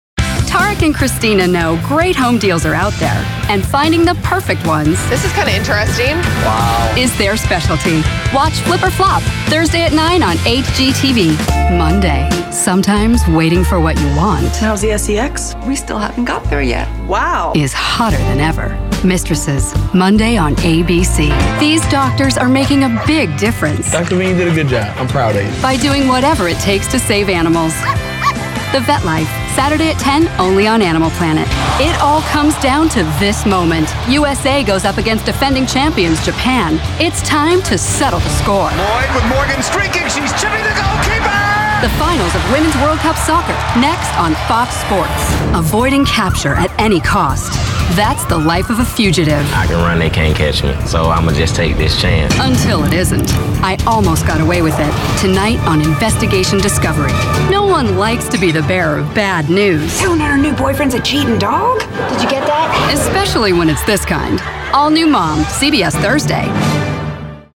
specializing in commercial + character voices.
DEMOS